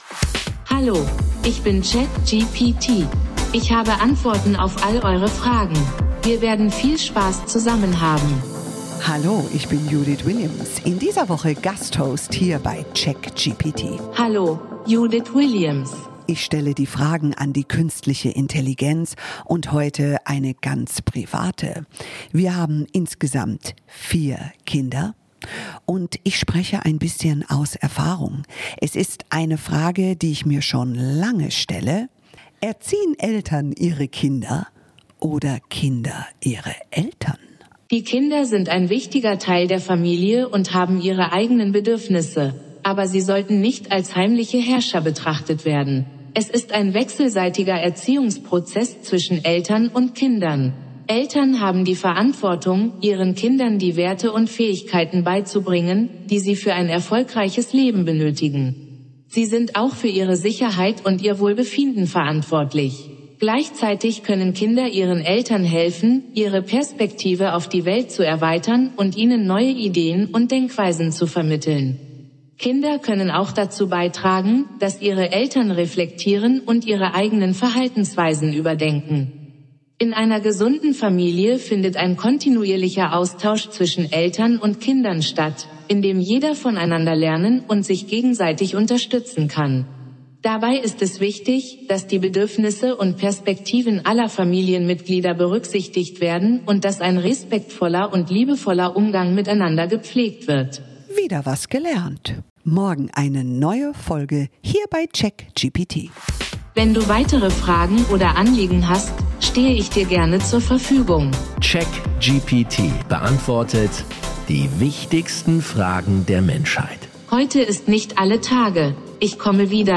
Judith Williams & KI
Gast-Moderatorin Judith Williams („Die Höhle der Löwen“) stellt in